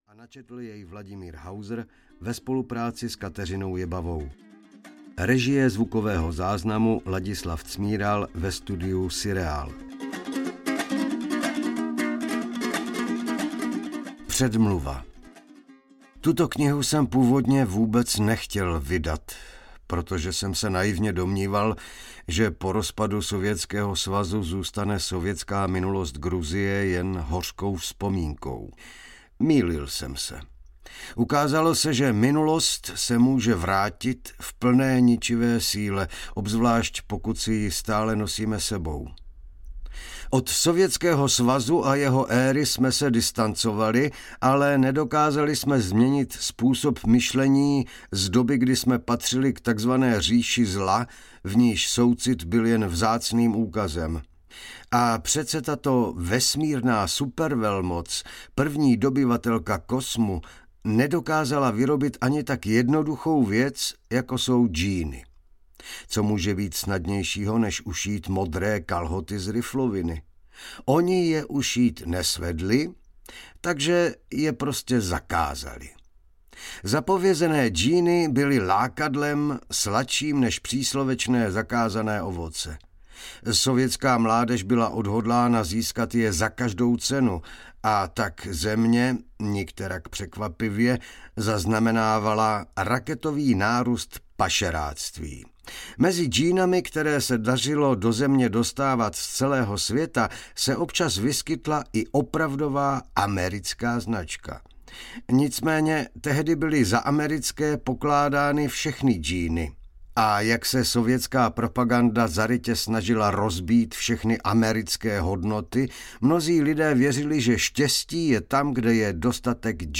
Džínová generace audiokniha
Ukázka z knihy